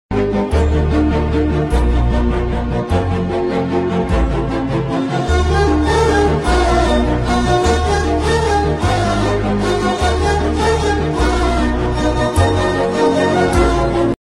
It just a short edit and it have not a good quality But..!!??